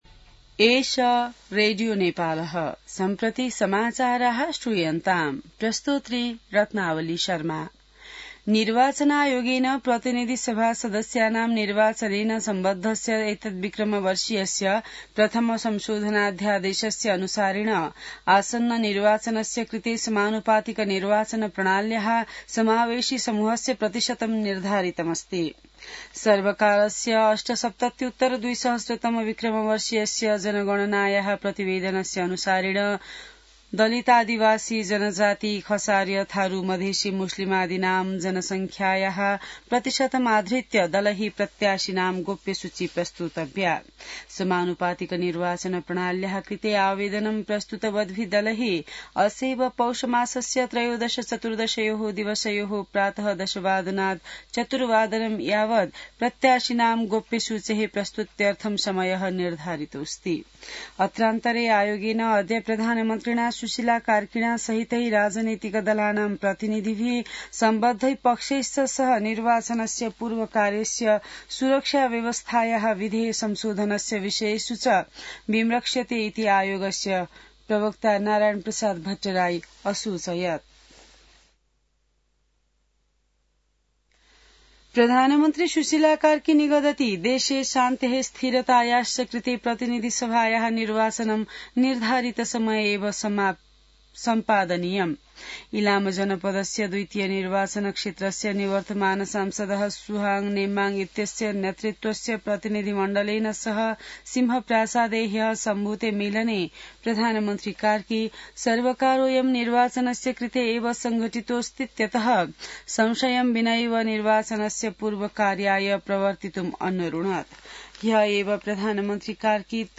संस्कृत समाचार : ७ पुष , २०८२